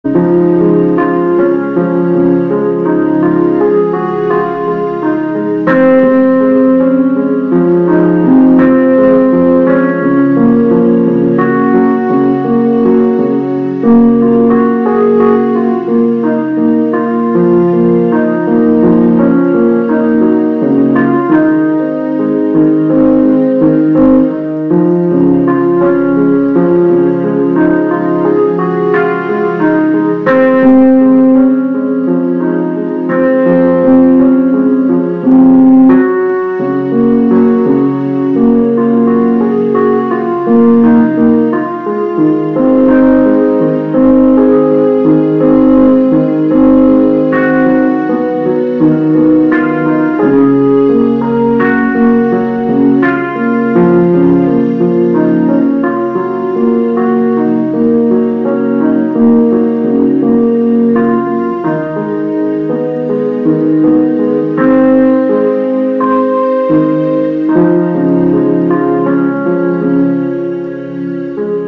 La musica che ascolterete, con la predominanza del piano rispetto agli archi, è essenziale e limpida nella sua costruzione, ma incisiva nella sua linea melodica, come l'azione degli angeli accanto a noi, nel proteggerci e difenderci.
VERSIONE CON "ARCHI"